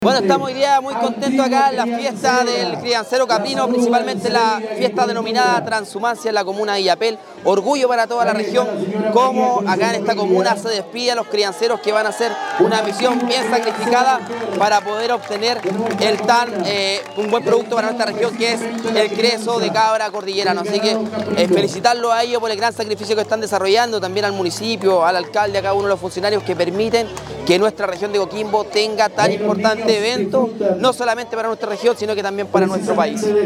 El gobernador Darwin Ibacache, presente en esta ceremonia, dijo que
GOBERNADOR-DARWIN-IBACACHE-1.mp3